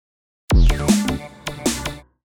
强节奏鼓声转场-1
【简介】： 超强节奏鼓声